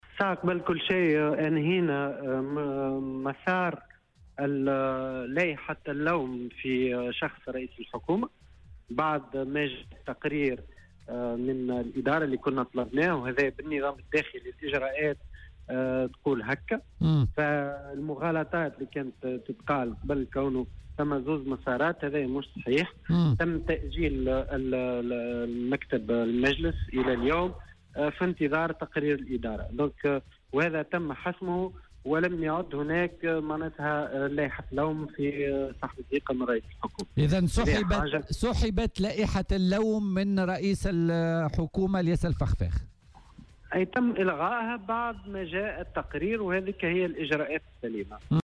وأضاف في مداخلة له اليوم في برنامج "بوليتيكا" أنه تم حسم الأمر ولم يعد هناك أي لائحة لوم لسحب الثقة من الفخفاخ.